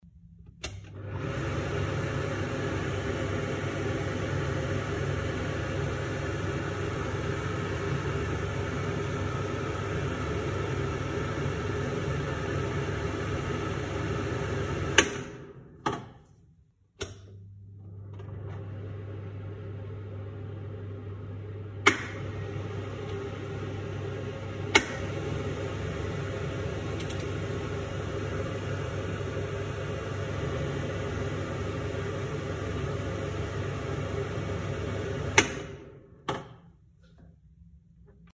На этой странице собраны различные звуки работающего вентилятора: от тихого гула компьютерного кулера до мощного потока воздуха из бытового прибора.
Звук работающей вытяжки